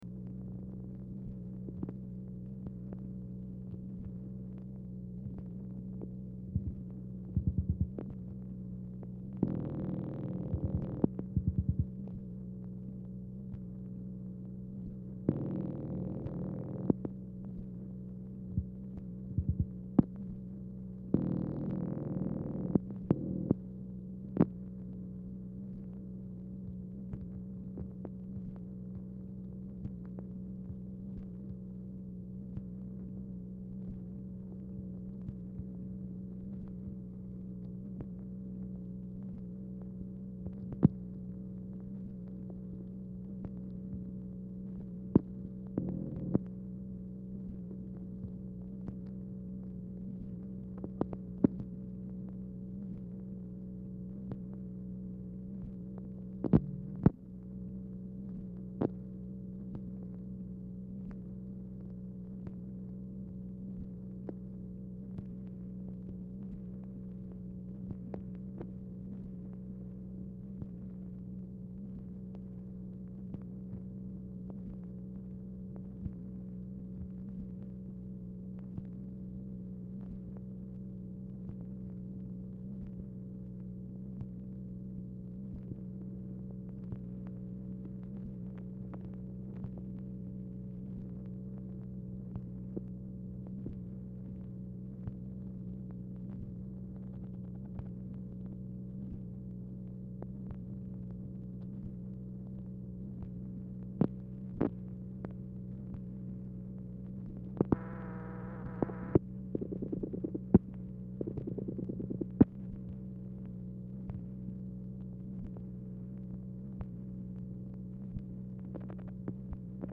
Telephone conversation # 1417, sound recording, MACHINE NOISE, 1/18/1964, time unknown | Discover LBJ
Format Dictation belt
White House Telephone Recordings and Transcripts Speaker 2 MACHINE NOISE